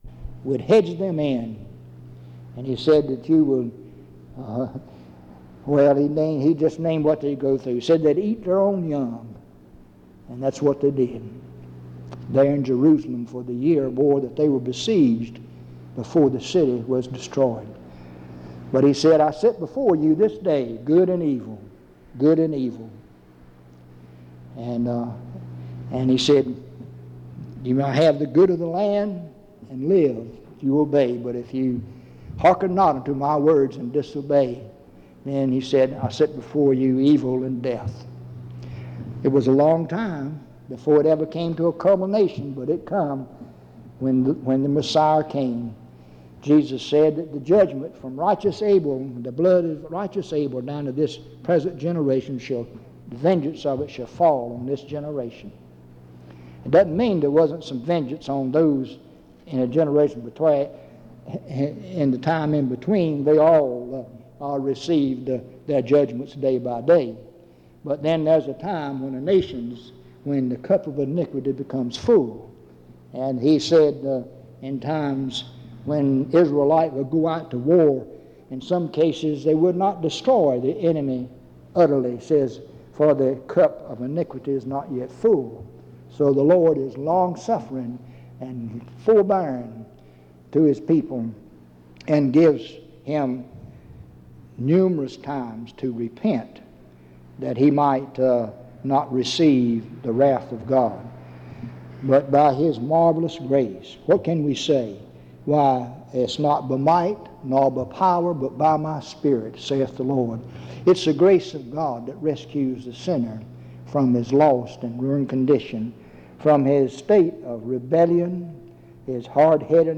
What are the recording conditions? In Collection: Reidsville/Lindsey Street Primitive Baptist Church audio recordings Miniaturansicht Titel Hochladedatum Sichtbarkeit Aktionen PBHLA-ACC.001_047-A-01.wav 2026-02-12 Herunterladen PBHLA-ACC.001_047-B-01.wav 2026-02-12 Herunterladen